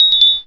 Cri d'Éoko dans Pokémon Rubis et Saphir.